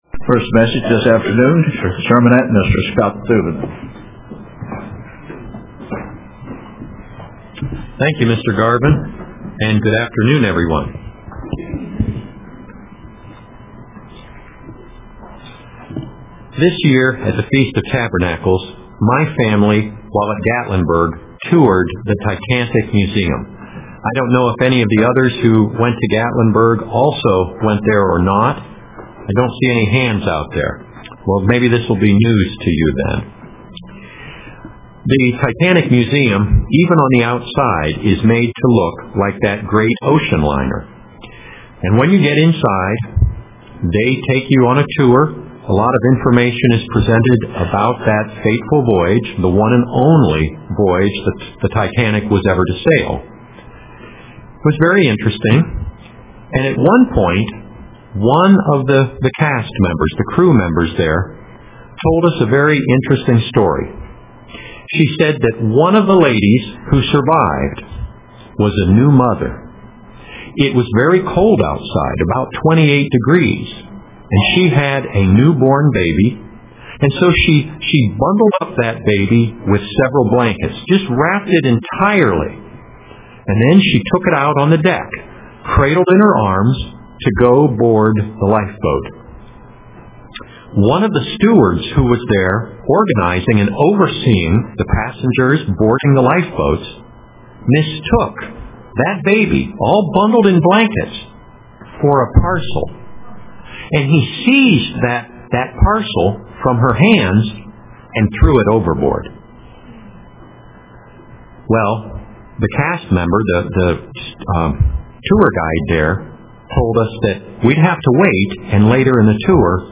Print Things are not always as their Appear UCG Sermon Studying the bible?